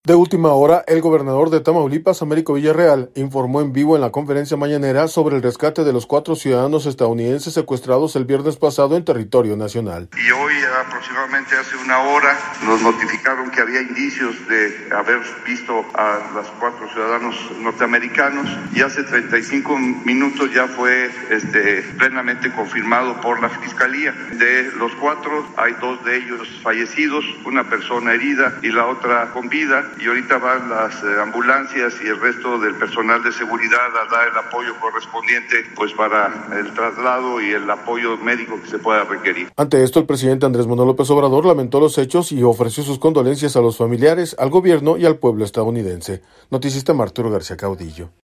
De última hora, el gobernador de Tamaulipas, Américo Villarreal, informó en vivo en la conferencia Mañanera, sobre el rescate de los cuatro ciudadanos estadounidenses secuestrados el viernes pasado en territorio nacional.